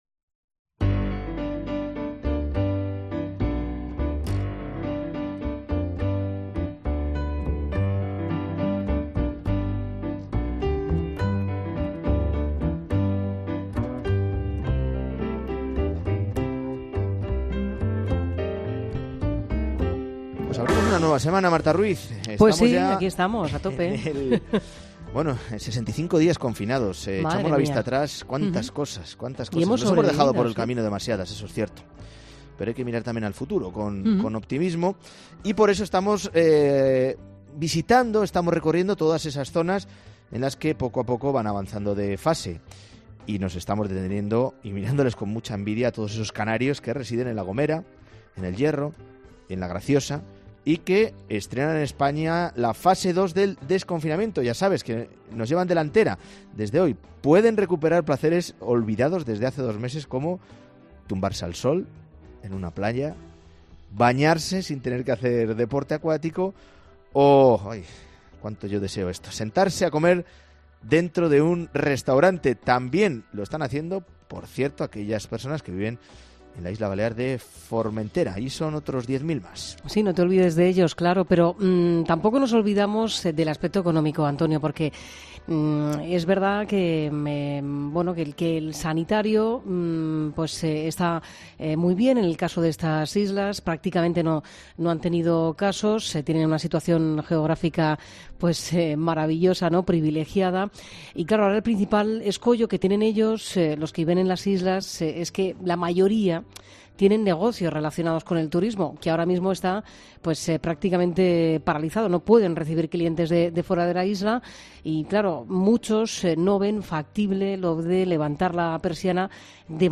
"Mediodía COPE" ha hablado con los propietarios de algunos negocios en las islas y que están sufriendo las conscuencias económicas por la falta de turistas